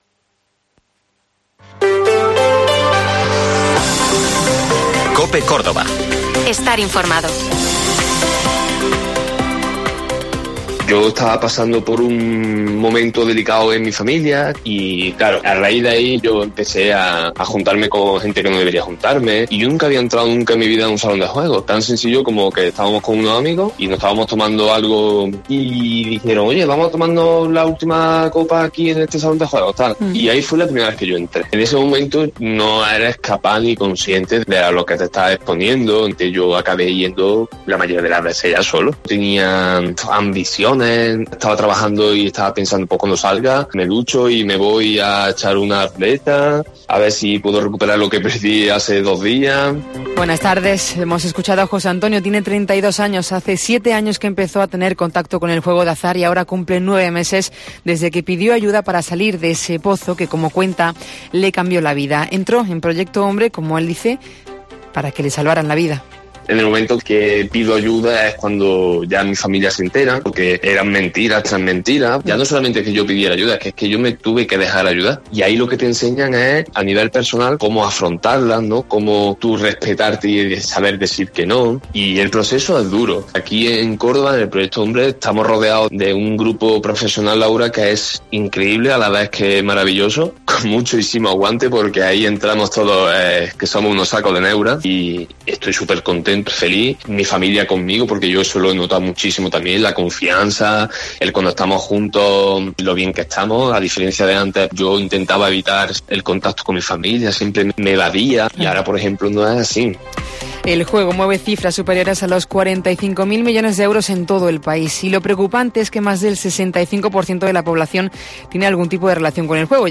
Mediodía COPE con el testimonio de una persona adicta al juego de azar y sus consecuencias en Córdoba, donde hasta 300 personas son atendidas al año.